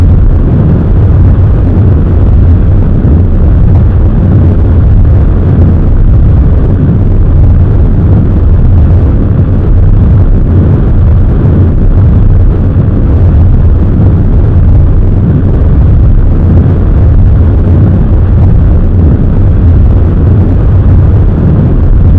thrust.wav